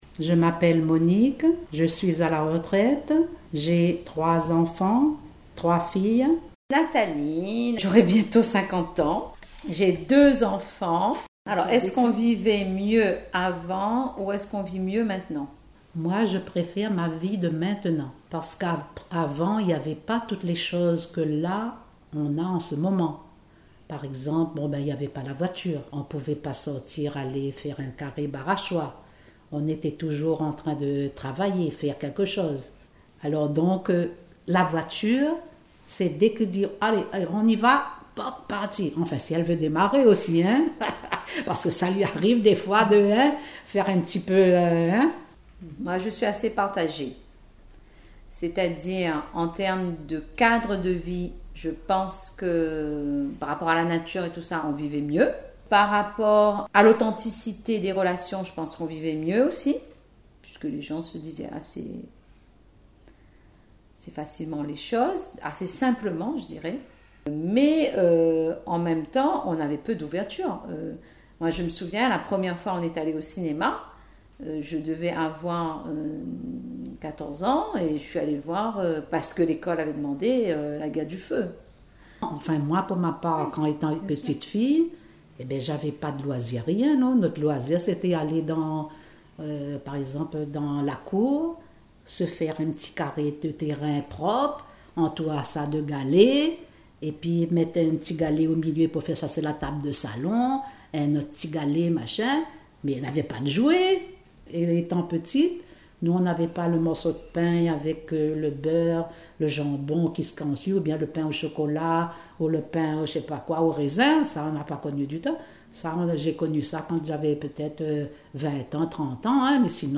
À la rencontre de deux femmes et de leurs histoires